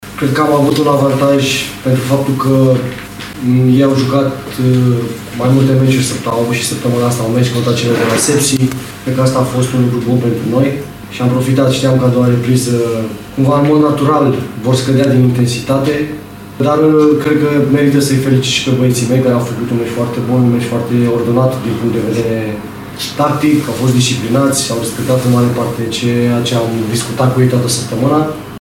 De partea cealaltă, Ovidiu Burcă (FC Voluntari) a recunoscut că programul încărcat al adversarei (cu meci și în Cupa României) a contat în cele din urmă: